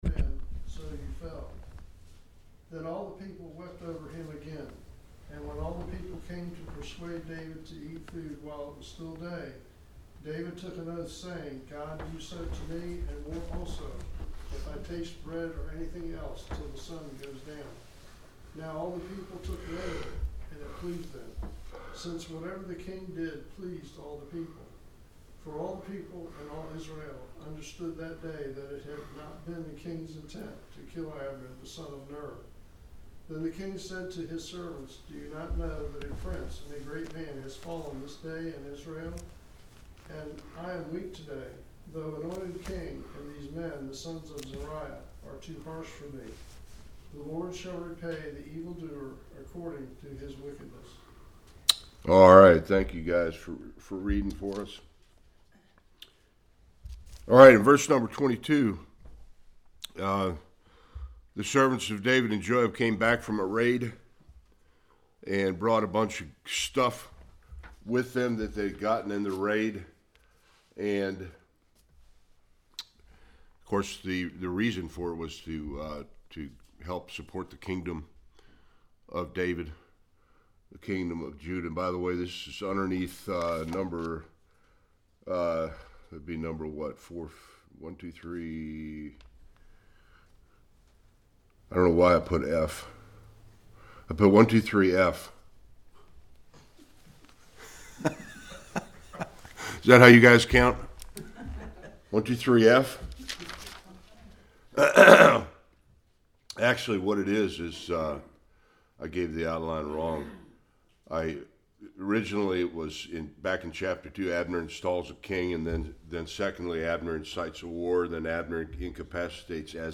1-12 Service Type: Sunday School Abner is honored by King David after being murdered by Joab.